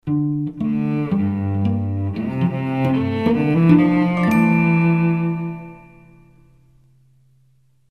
Fünfteilige Kinderhörspielserie
Trompete, Flügelhorn
Querflöte, Altflöte
Sopran
Indisches Harmonium, Schlagwerk, Klangschale, Keyboards